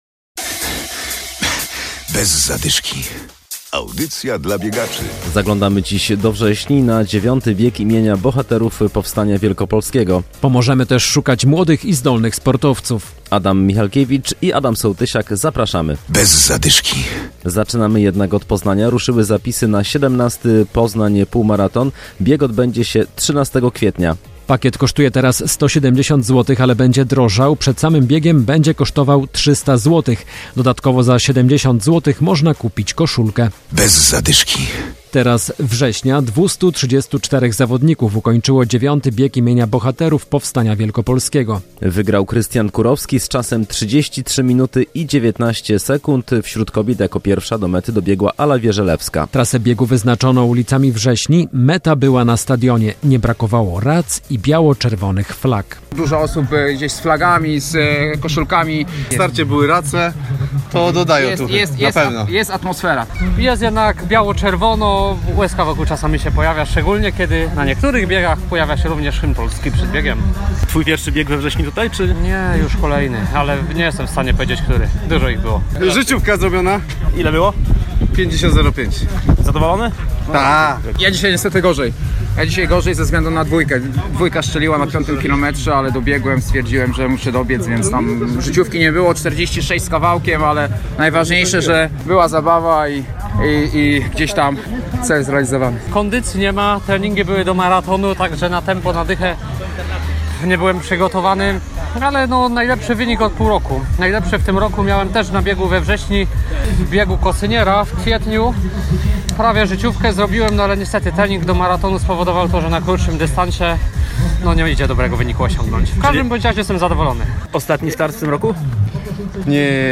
Relacja z Biegu imienia Bohaterów Powstania Wielkopolskiego we Wrześni.